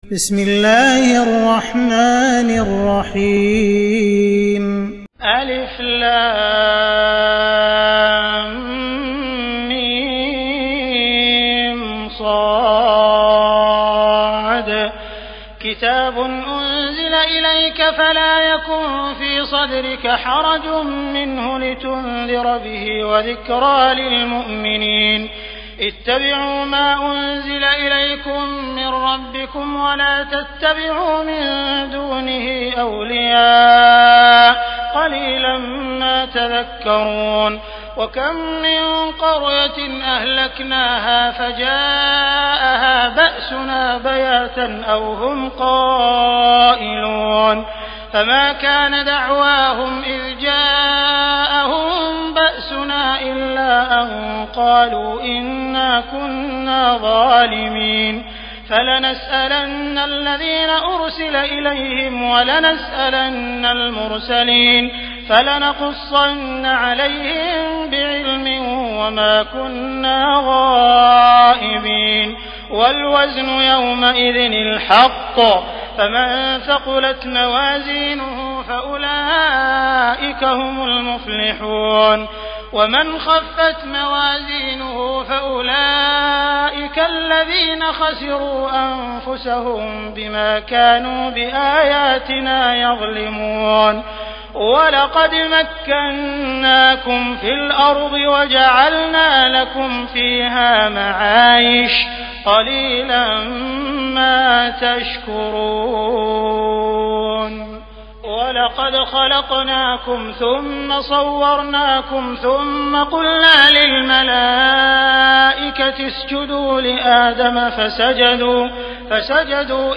تراويح الليلة الثامنة رمضان 1418هـ من سورة الأعراف (1-64) Taraweeh 8 st night Ramadan 1418H from Surah Al-A’raf > تراويح الحرم المكي عام 1418 🕋 > التراويح - تلاوات الحرمين